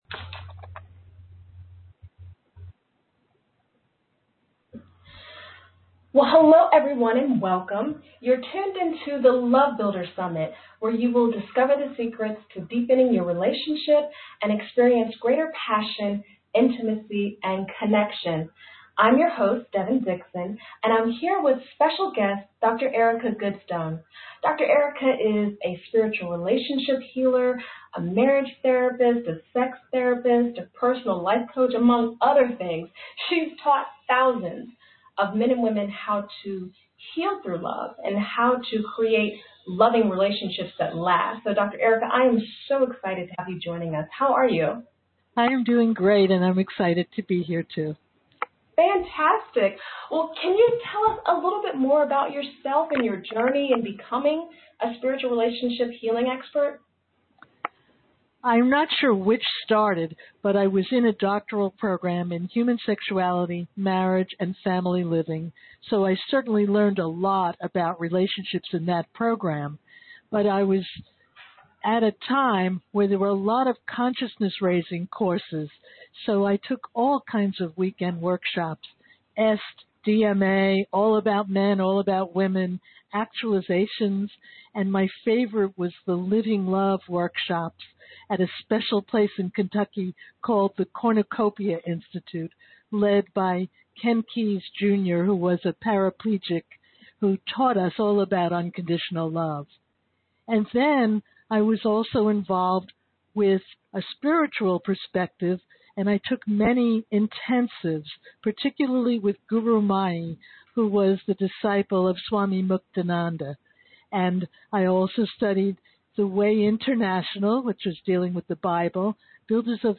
LOVE BUILDER SUMMIT AUDIO INTERVIEW